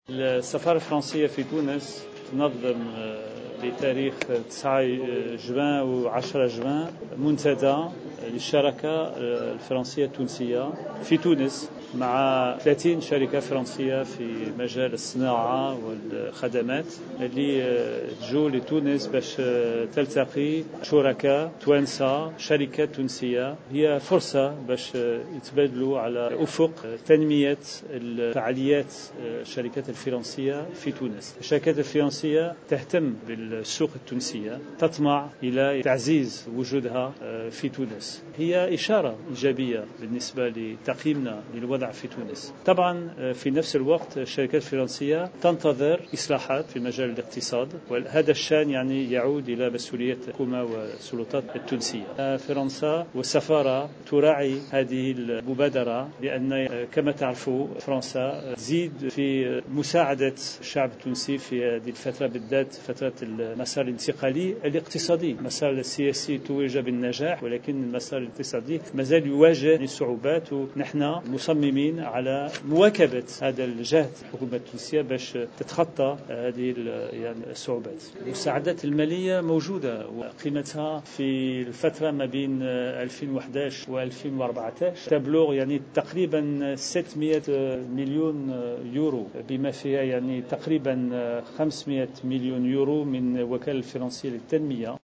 أكد سفير فرنسا بتونس، فرونسوا غويات، خلال ندوة صحفية انتظمت الاربعاء بمقر السفارة، ان 30 مؤسسة ستشارم في الدورة الاولى للمنتدى التونسي الفرنسي الذي سيلتئم يومي 9 و10 جوان 2015 بتونس مؤكدا أنها بالأساس شركات صغرى ومتوسطة ناشطة في مجالات مختلفة على غرار الصناعة والتكنولوجيات الحديثة والبيئة والخدمات.